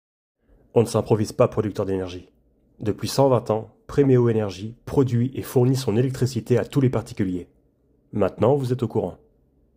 Bandes-son